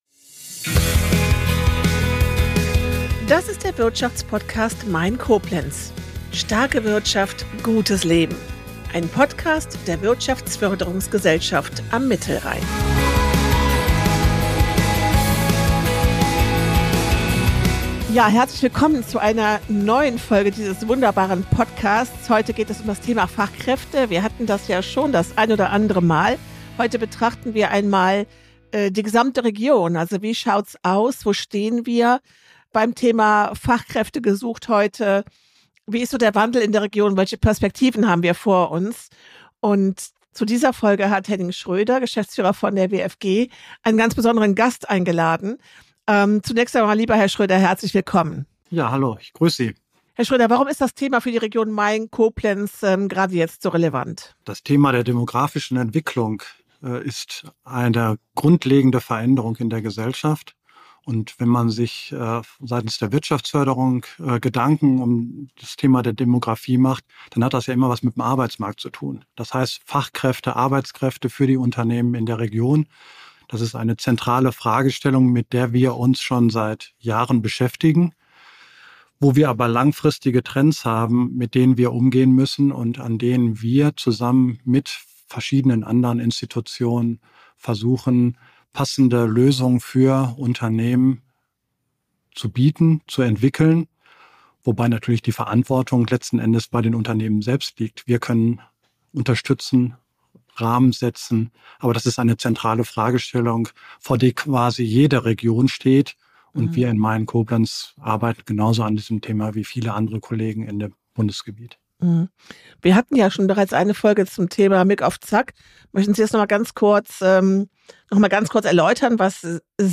Zudem werden die Resilienz der Region und die Notwendigkeit, sich auf zukünftige Entwicklungen einzustellen, thematisiert. Das Gespräch dokumentiert natürlich auch, wie wichtig die Zusammenarbeit zwischen Wirtschaftsförderung und Arbeitsagentur ist.